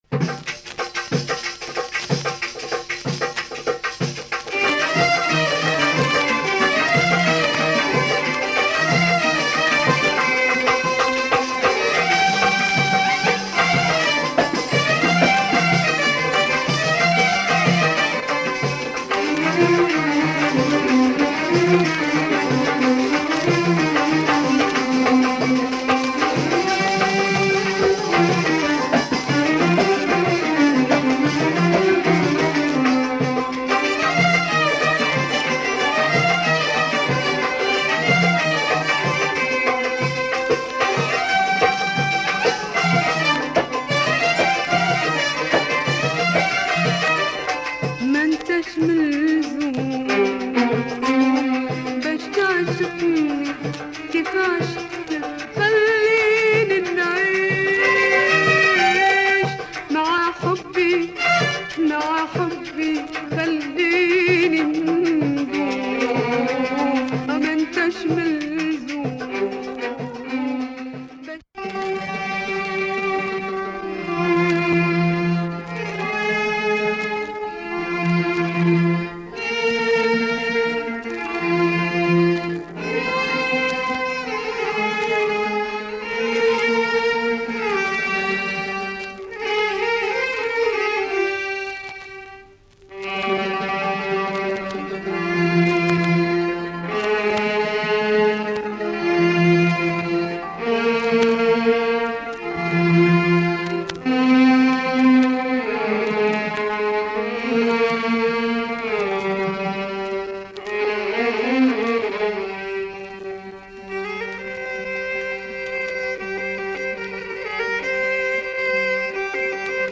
Moroccan female singer
Disc is well pressed and in excellent shape.